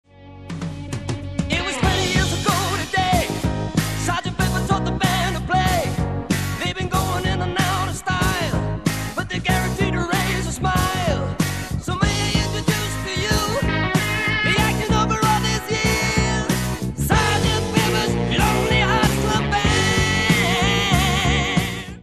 RECORDED AND MIXED AT CHEROKEE STUDIOS, LOS ANGELES